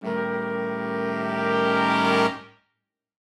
Index of /musicradar/gangster-sting-samples/Chord Hits/Horn Swells
GS_HornSwell-A7b2b5.wav